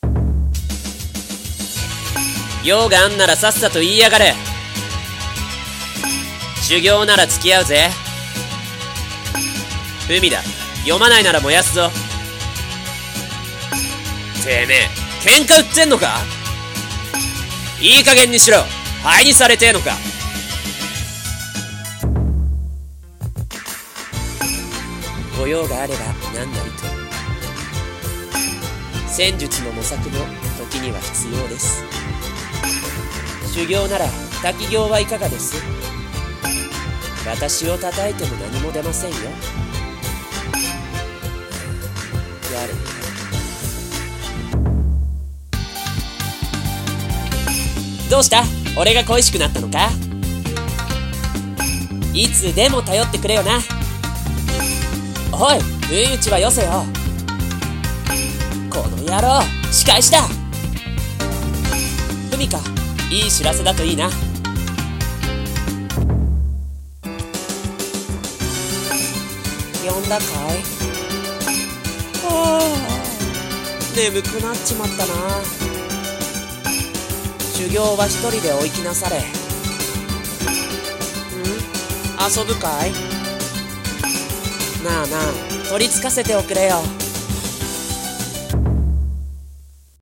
【演じ分け台本】妖怪格ゲーアプリ 男声用/前編【和風】